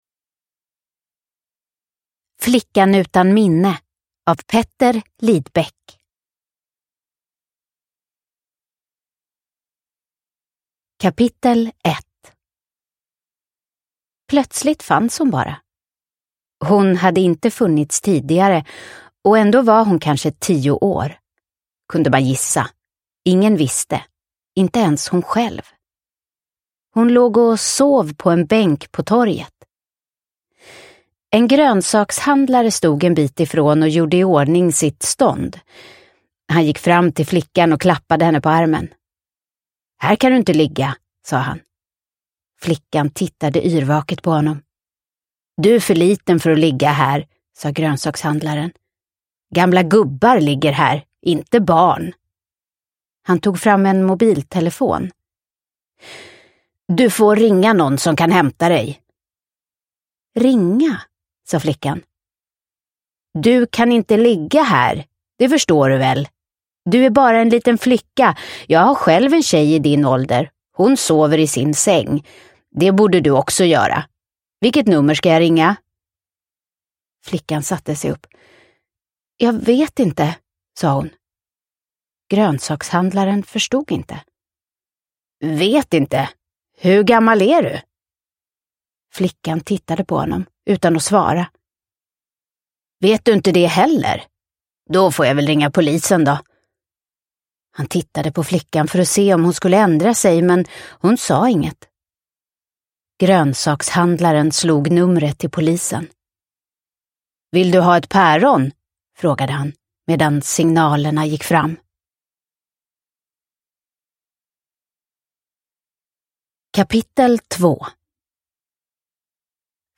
Flickan utan minne – Ljudbok – Laddas ner